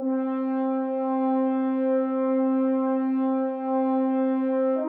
Rusty Horn.wav